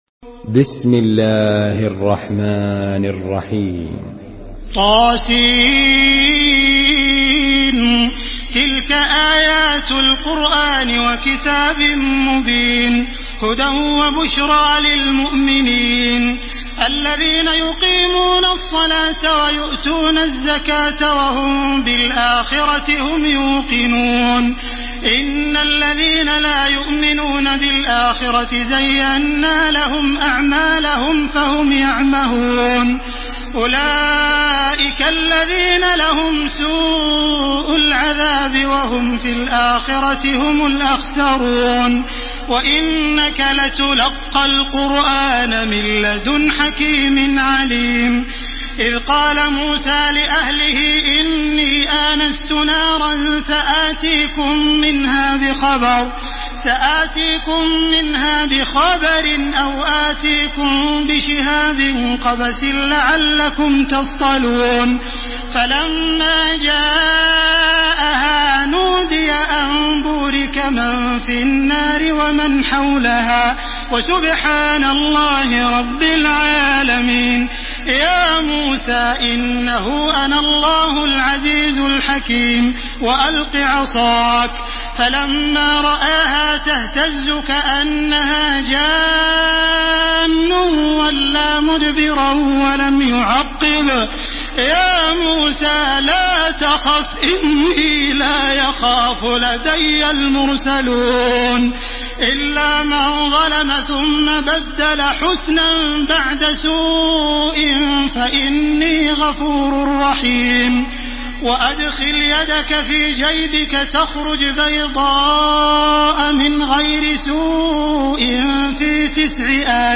دانلود سوره النمل توسط تراويح الحرم المكي 1431
مرتل